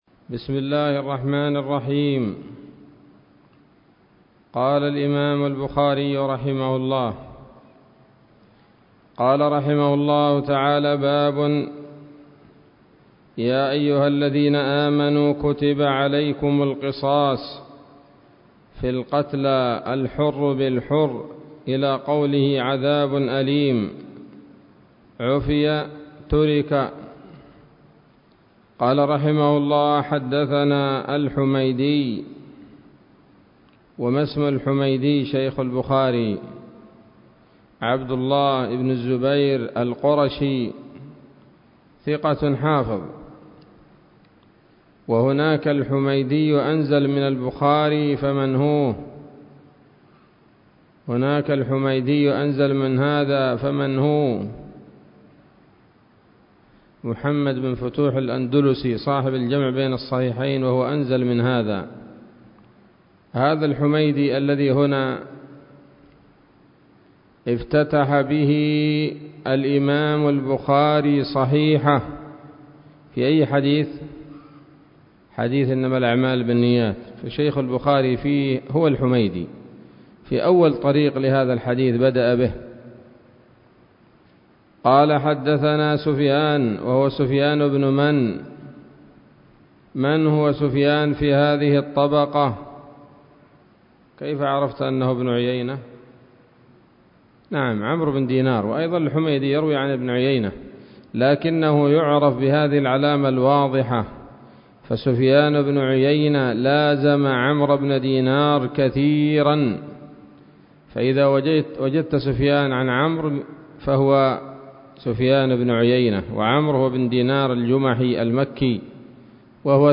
الدرس العشرون من كتاب التفسير من صحيح الإمام البخاري